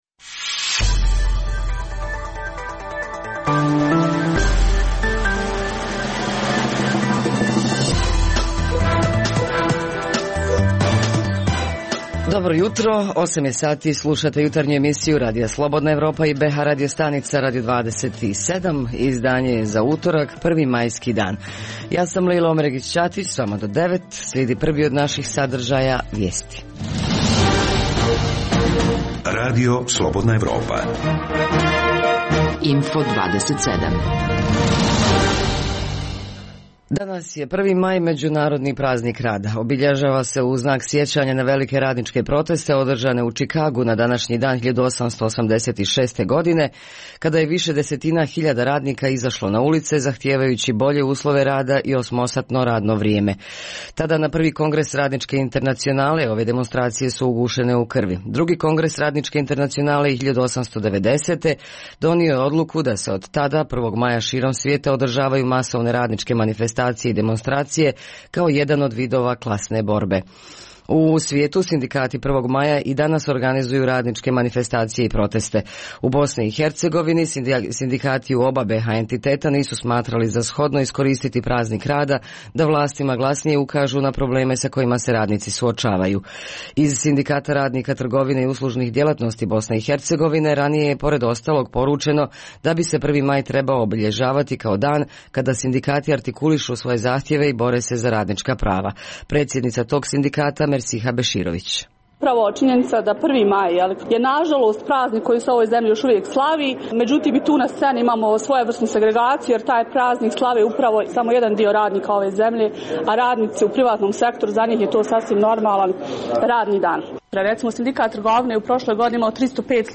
Naša jutarnja emisija gotovo je u cijelosti posvećena Međunarodnom prazniku rada. U prvom dijelu emisije obići ćemo Sarajevo, Banja Luku i Mostar i od naših dopisnica čuti kako će u tim gradovima biti obilježen praznik.